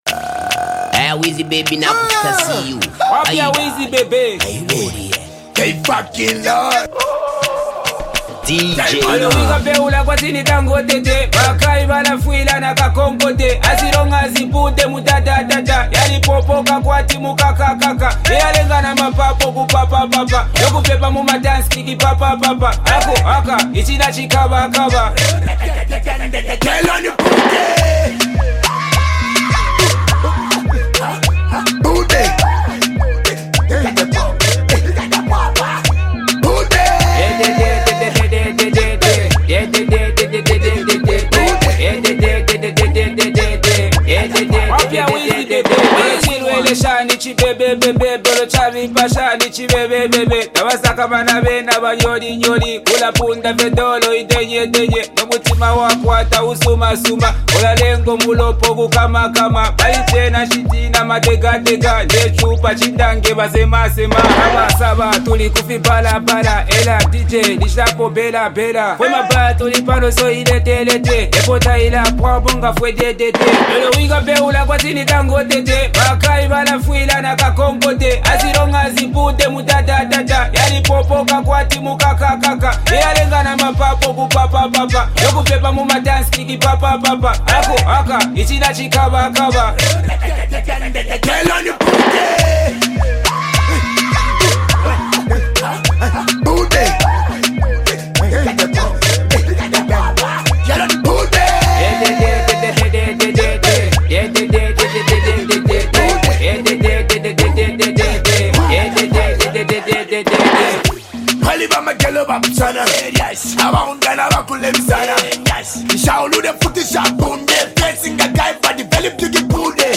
vibrant and energetic
contemporary Afro-pop and dance vibes